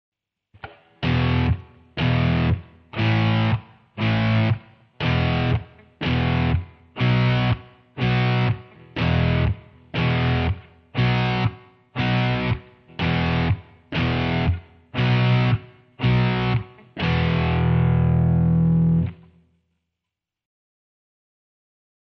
Power chord exercise 2 [MP3]
powechord Ex. 2.mp3